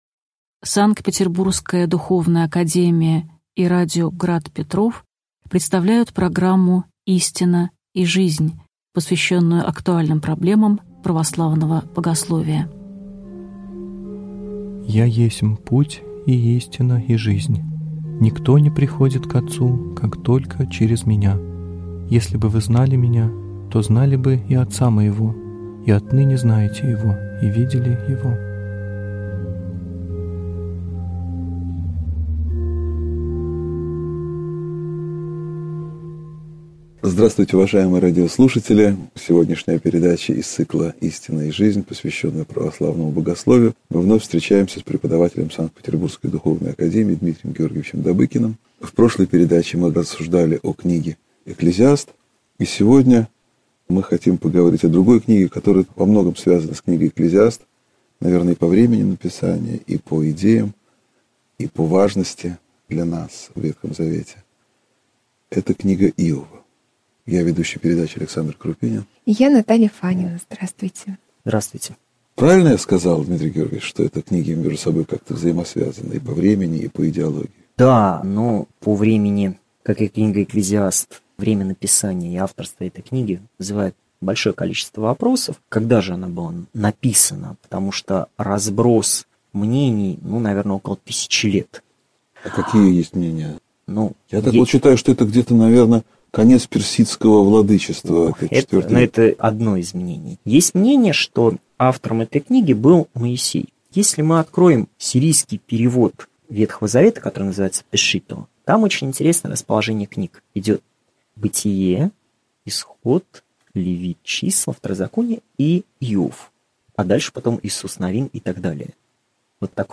Аудиокнига Экклезиаст. Иов (часть 2) | Библиотека аудиокниг